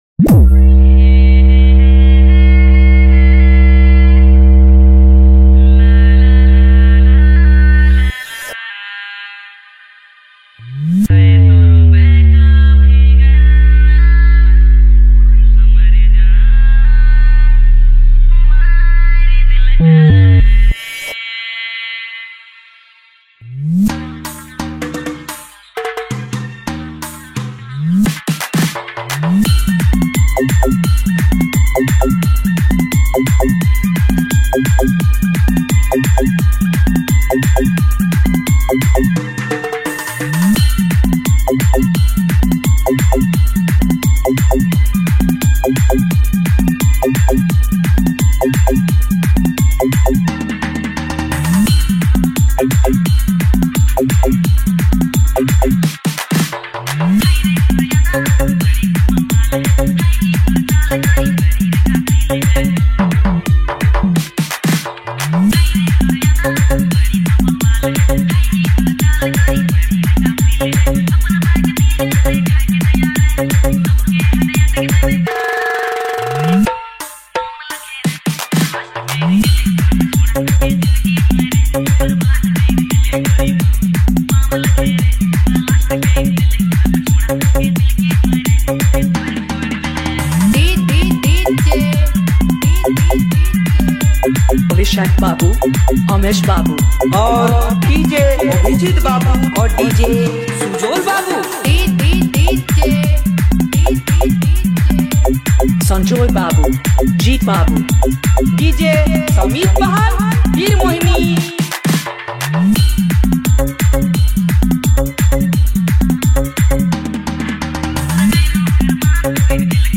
Dj Remixer
New Nagpuri Dj Song 2025